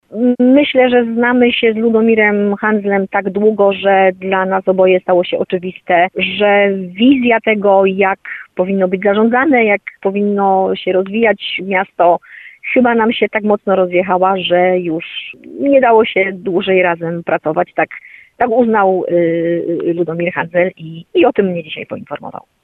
Magdalena Majka w rozmowie z RDN Nowy Sącz poinformowała, że nie padły ze strony prezydenta zarzuty co do tego, jak była pełniona przez nią funkcja zastępcy prezydenta.